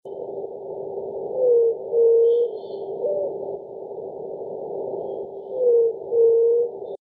Yerutí Común (Leptotila verreauxi)
Nombre en inglés: White-tipped Dove
Localidad o área protegida: Reserva Ecológica Costanera Sur (RECS)
Condición: Silvestre
Certeza: Vocalización Grabada